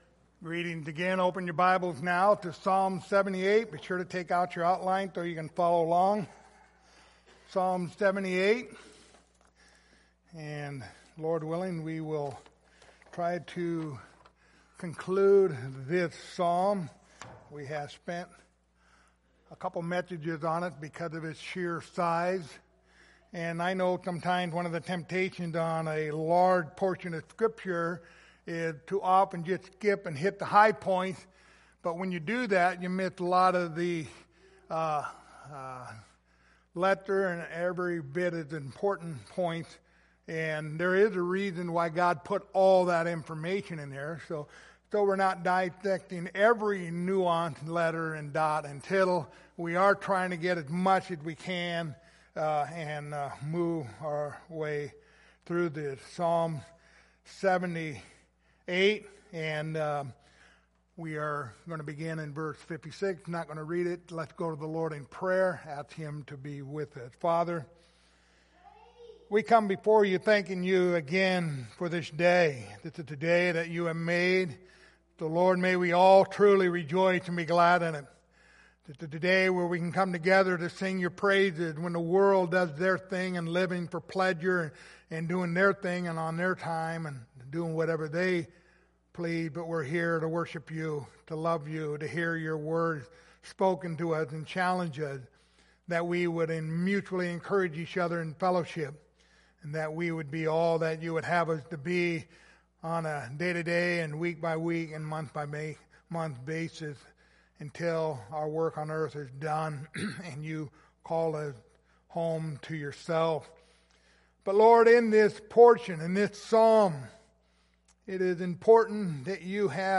Passage: Psalms 78:56-72 Service Type: Sunday Morning